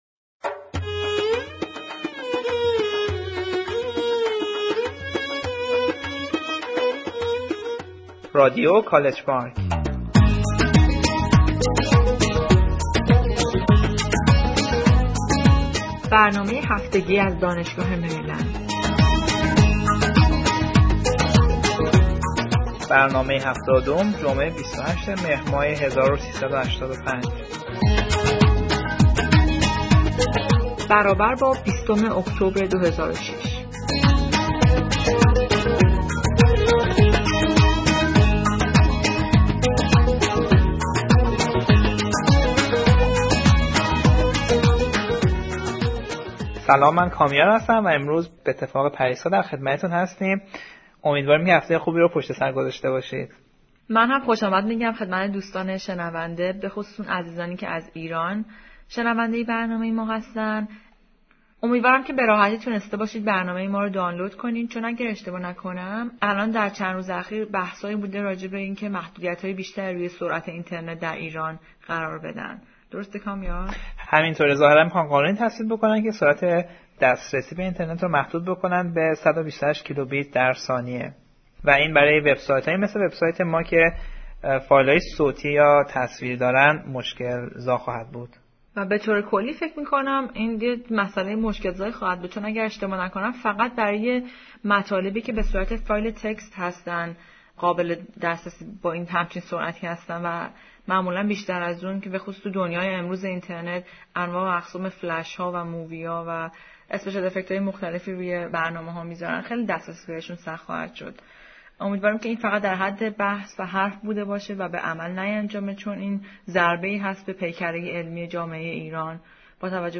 A Poem in Araki Dialect